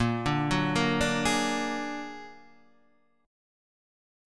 A#+7 Chord